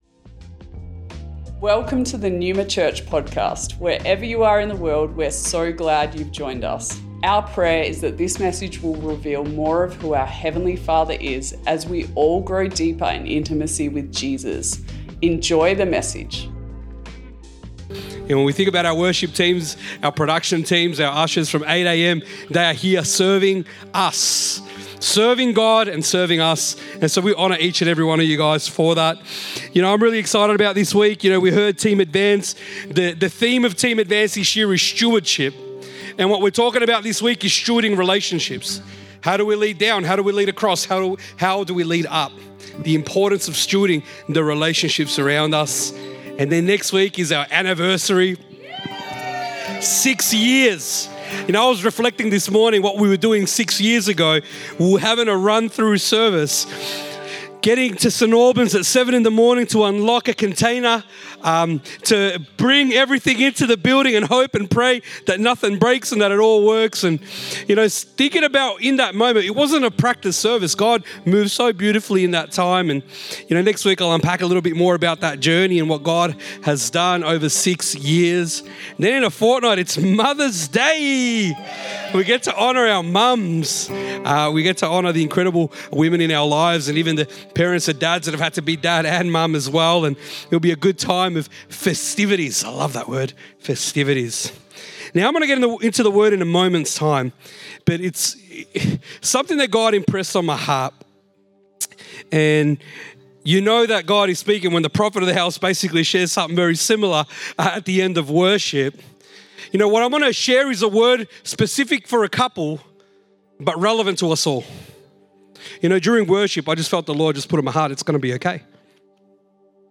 Originally recorded at Neuma Melbourne West May 5th 2024